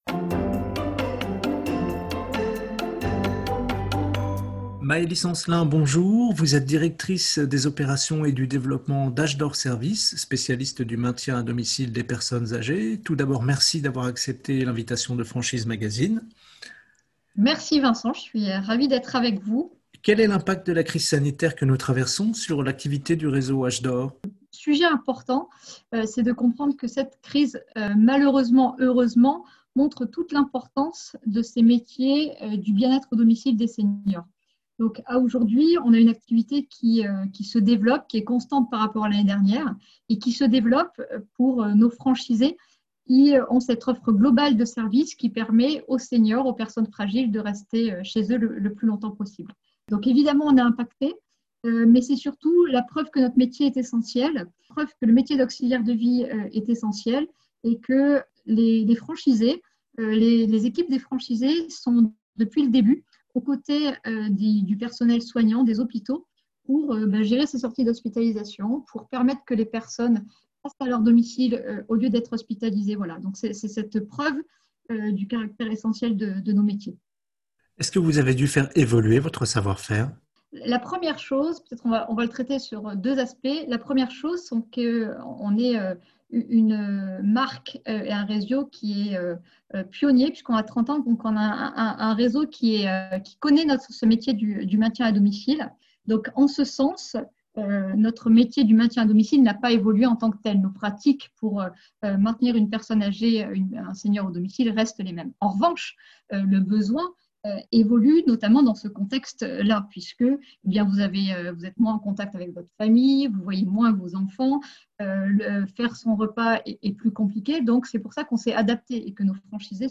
Au micro du podcast Franchise Magazine : la Franchise Age d’or Services - Écoutez l'interview